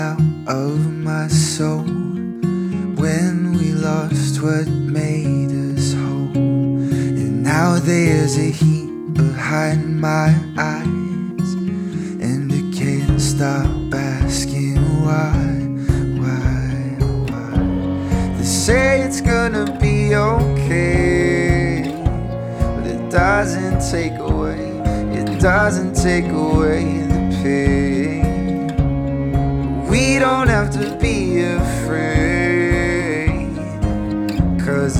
Плавные вокальные партии и чувственный бит
Глубокий соул-вокал и плотные хоровые партии
Singer Songwriter R B Soul
Жанр: R&B / Соул